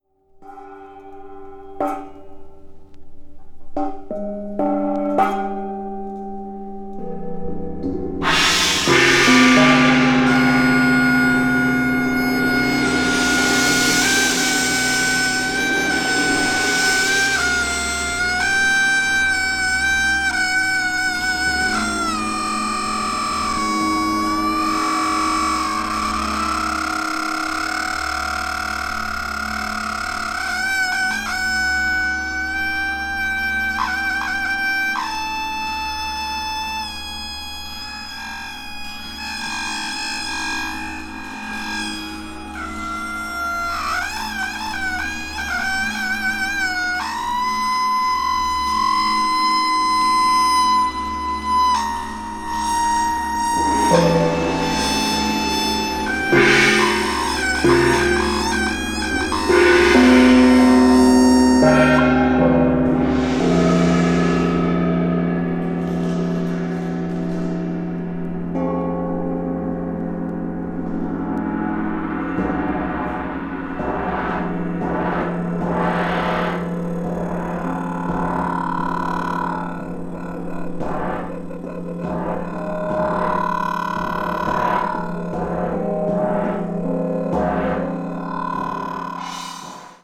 avant-jazz   free improvisation   free jazz   free music